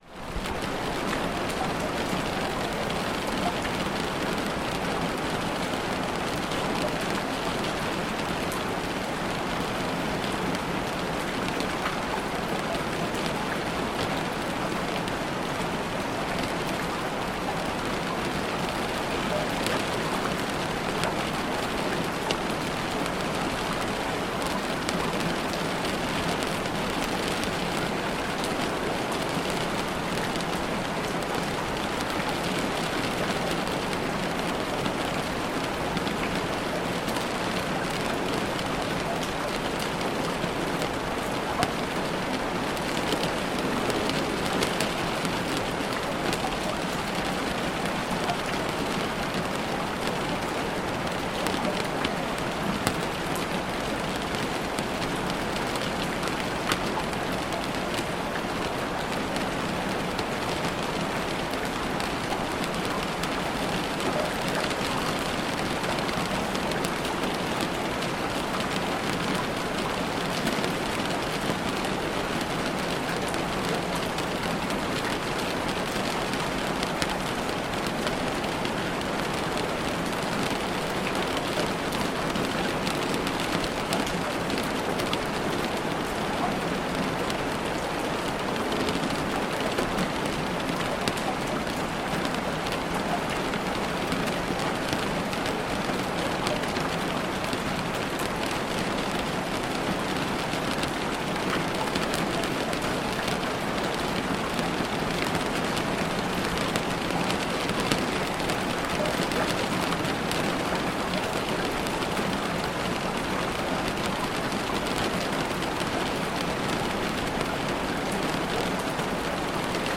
(Ads may play before the episode begins.)The sky opens with a low growl of thunder.